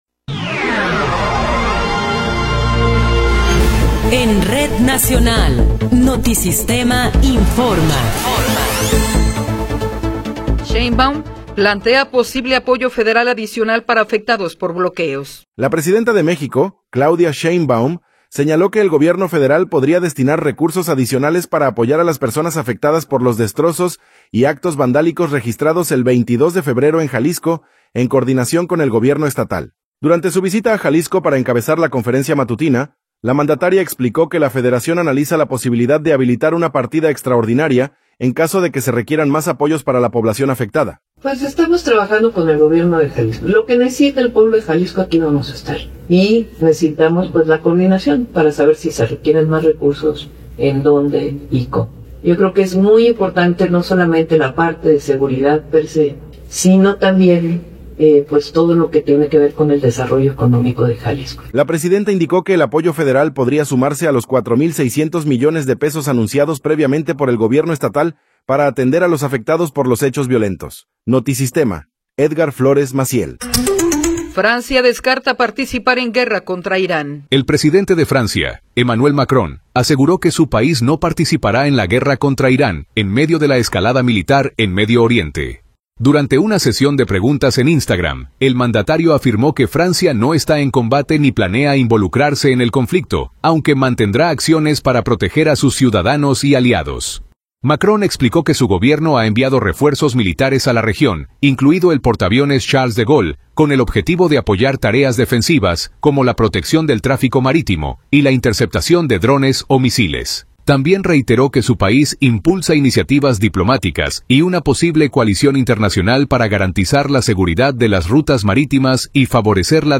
Noticiero 11 hrs. – 6 de Marzo de 2026